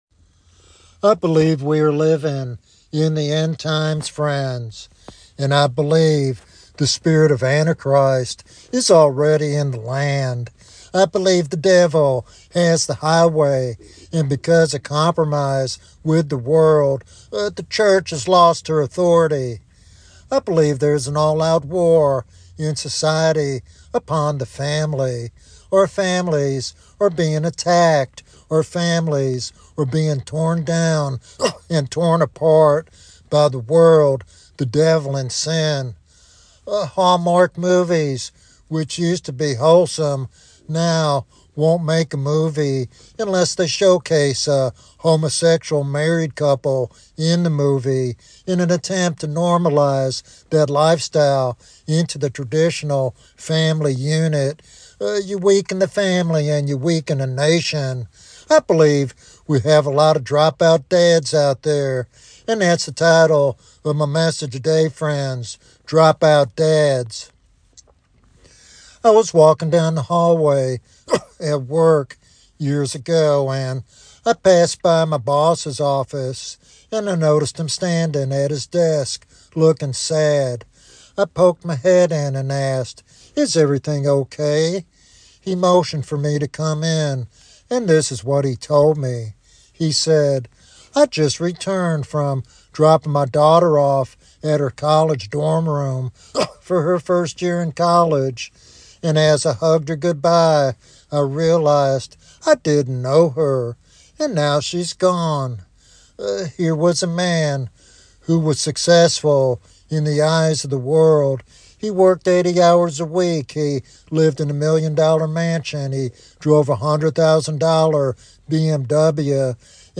This sermon offers practical guidance and a heartfelt call to reclaim godly fatherhood for the sake of the family, church, and society.